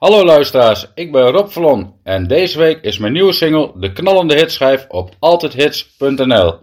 levenslied